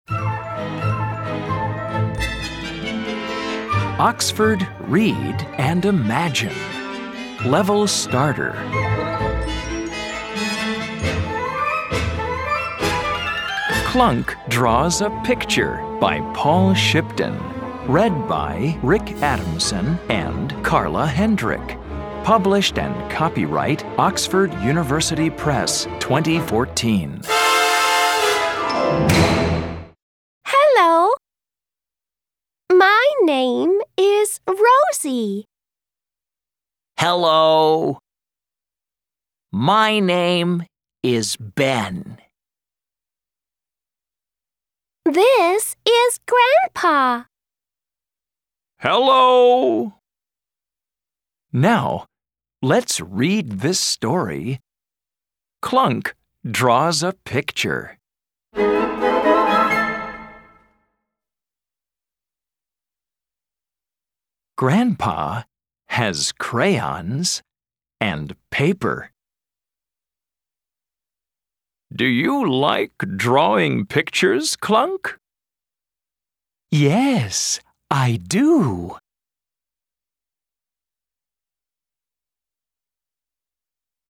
Track 1 Clunk Draws US.mp3